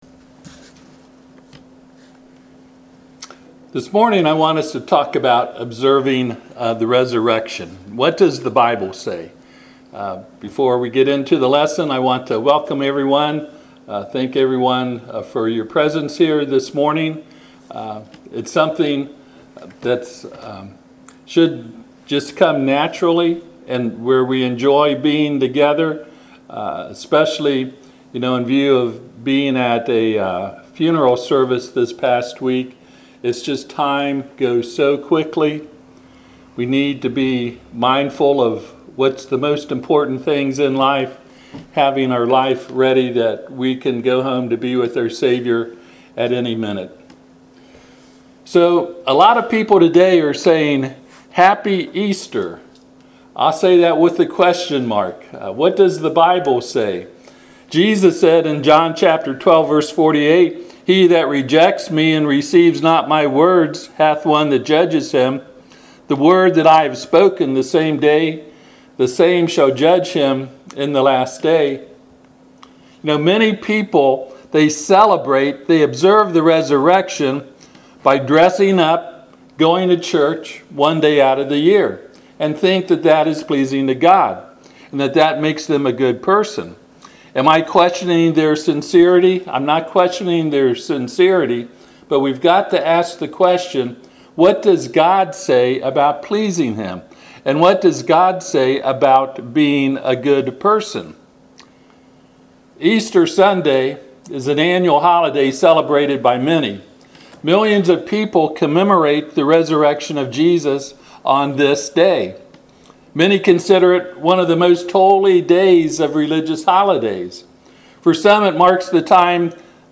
1 Corinthians 11:22-25 Service Type: Sunday AM https